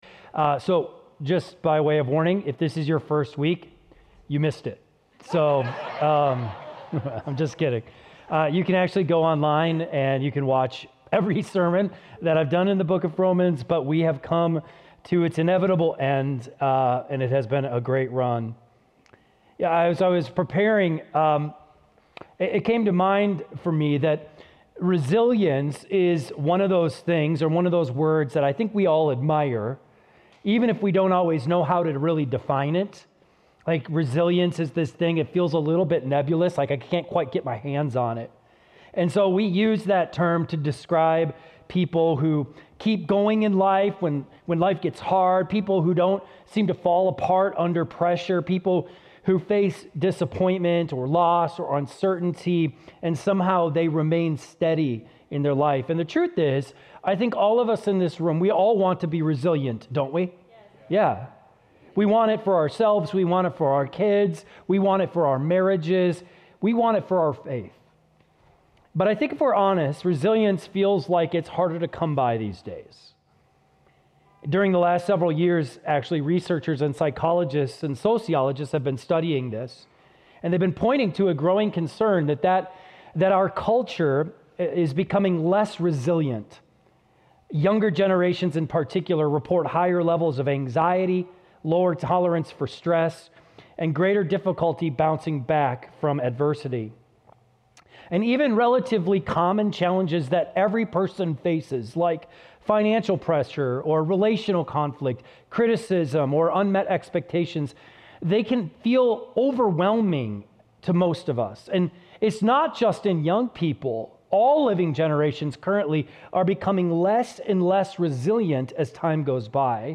keyboard_arrow_left Sermons / Romans Series Download MP3